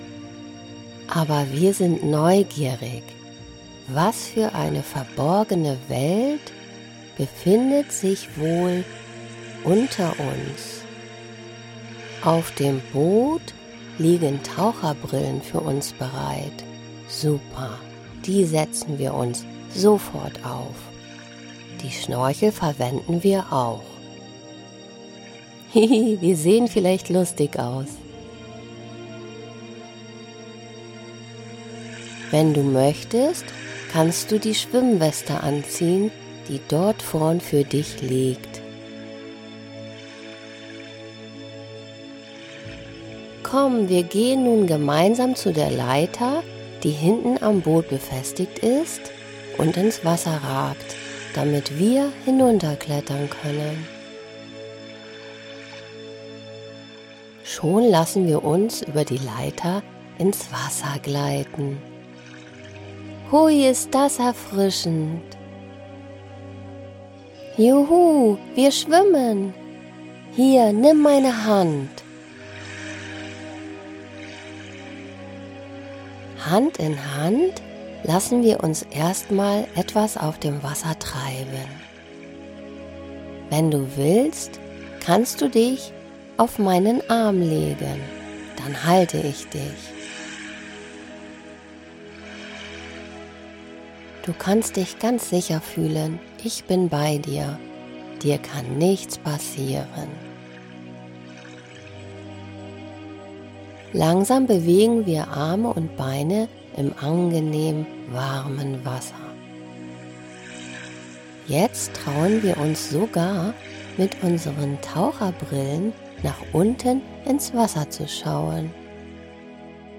Ich erzähle dir ... ganz ruhig ... und entspannend ... Gutenachtgeschichten zum Einschlafen.
Traummusik spielt währenddessen im Hintergrund und hilft beim Weiterträumen nach dem Ende der Traumreise.